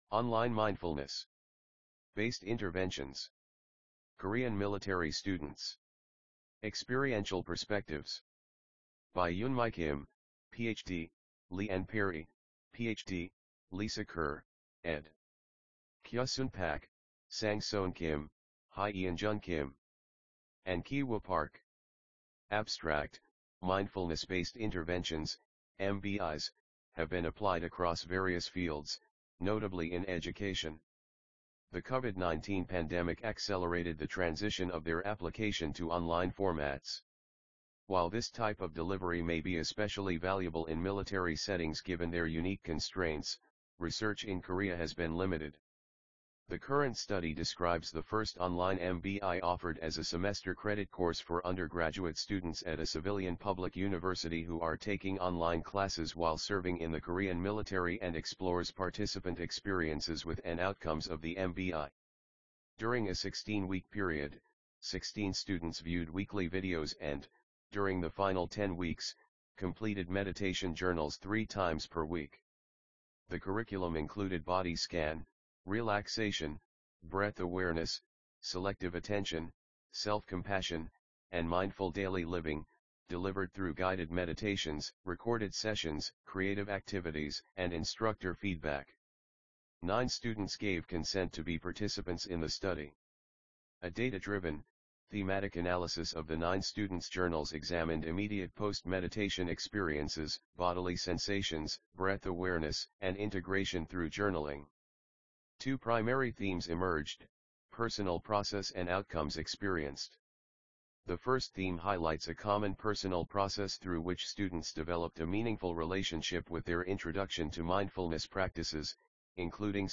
IPME_2025_Online Mindfulness_Perry et al_AUDIOBOOK.mp3